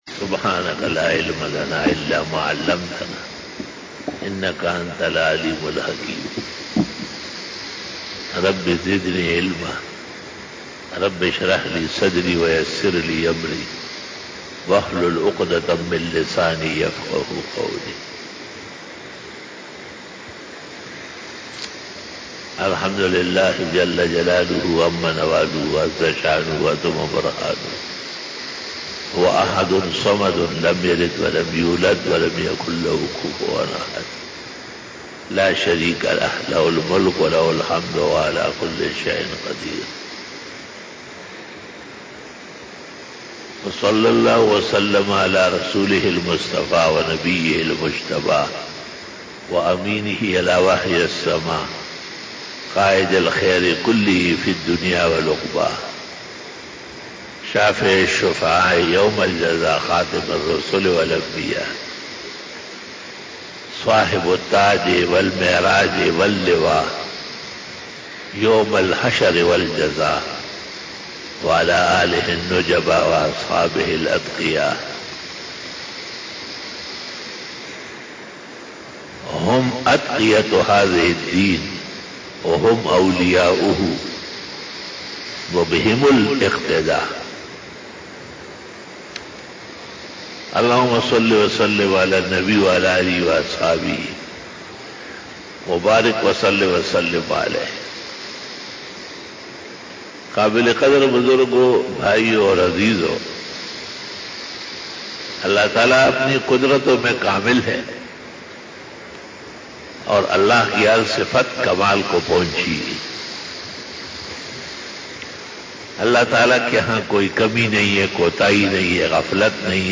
20 BAYAN E JUMA TUL MUBARAK 26 June 2020 (04 Zil qaadah 1441H)
Khitab-e-Jummah 2020